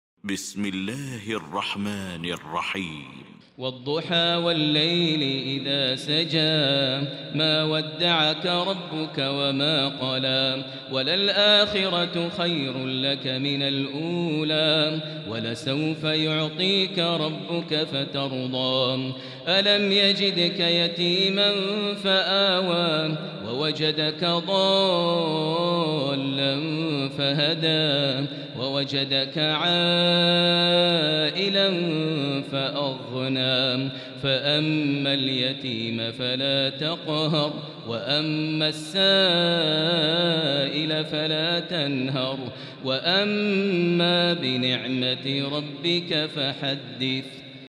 المكان: المسجد الحرام الشيخ: فضيلة الشيخ ماهر المعيقلي فضيلة الشيخ ماهر المعيقلي الضحى